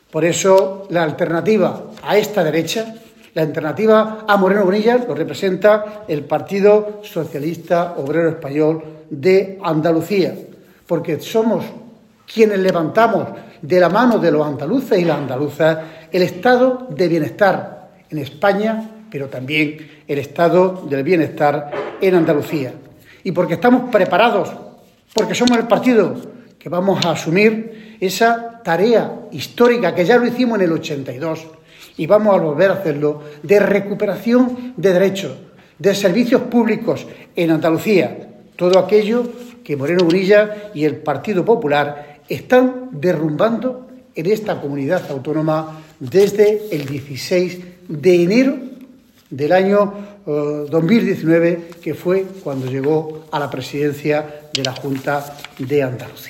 En rueda de prensa, Reyes manifestó que “la grandeza de este proceso de democracia interna” del PSOE para elegir a su secretario general “tiene muy desquiciados a los responsables del PP”, puesto que el propio Moreno Bonilla “ha mandado a todos sus voceros a arremeter contra el PSOE”.
Cortes de sonido